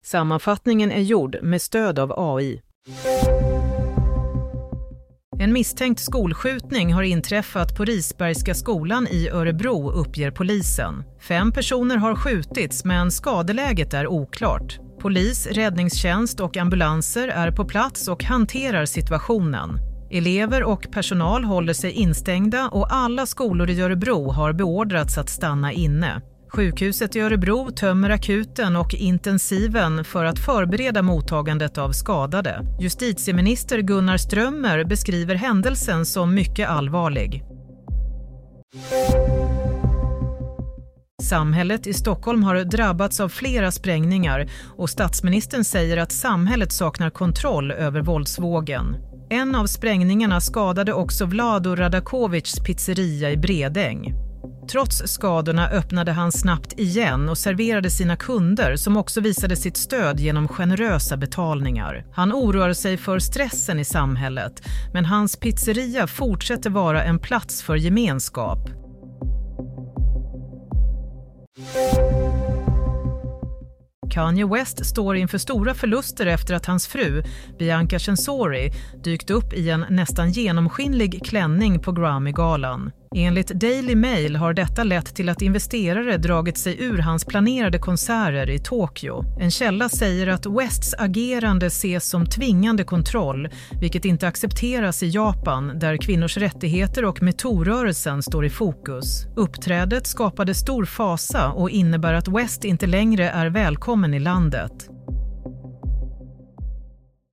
Nyhetssammanfattning – 4 februari 16.00
Sammanfattningen av följande nyheter är gjord med stöd av AI.